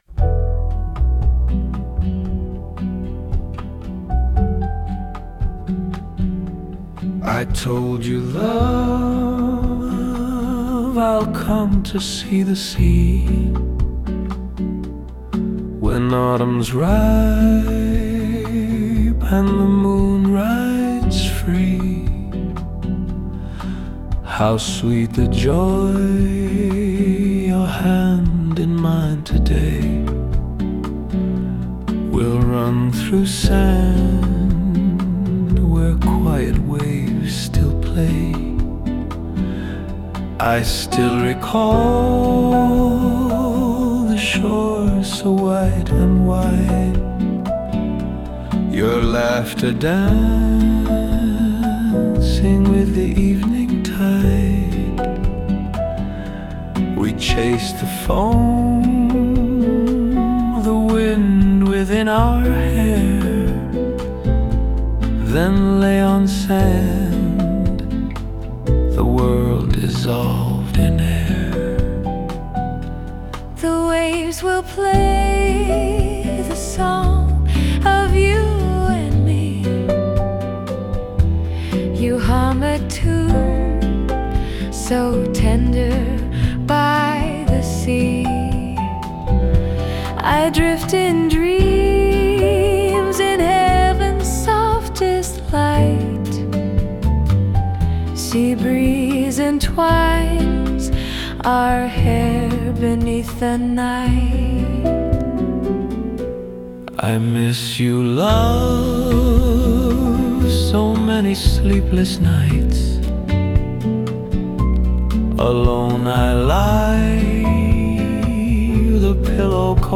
musical lyric version, keeping it natural and singable in English — something that could fit a slow waltz, bossa nova, or soft acoustic ballad